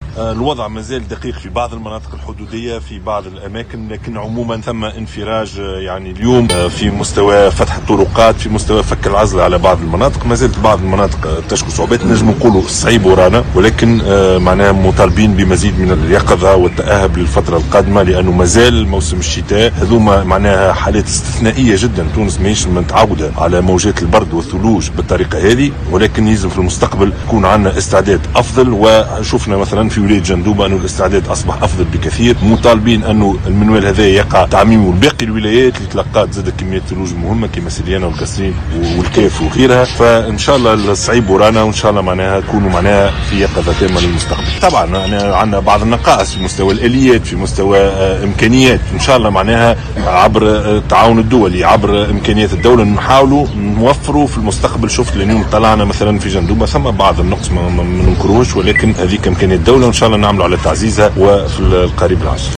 قال رئيس الحكومة يوسف الشاهد، خلال زيارة أداها اليوم الأحد إلى ولاية جندوبة، إن الوضع مازال دقيقا في بعض المناطق خاصة الحدودية، جراء تساقط الثلوج، رغم انفراج الأوضاع في مناطق أخرى.